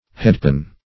Headpan \Head"pan`\ (-p[a^]n`), n.